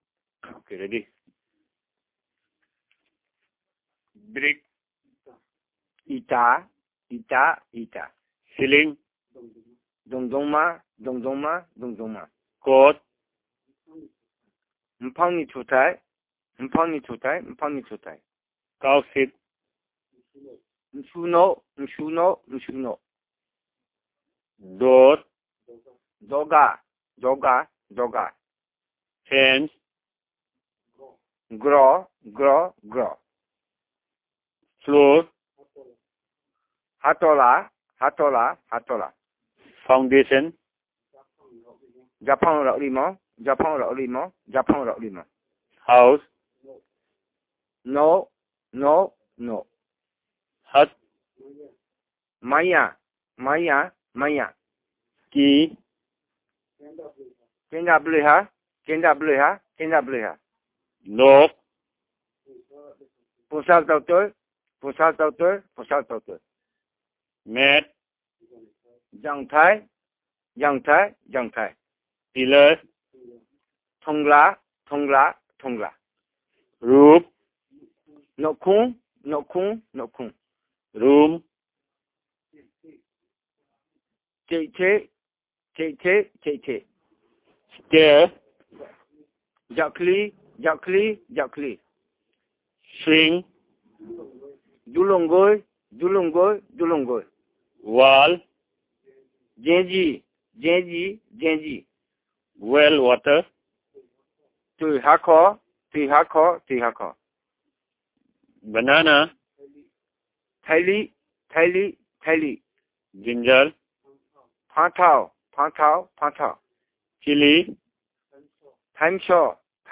dc.description.elicitationmethodInterview method
dc.type.discoursetypeWord list elicitation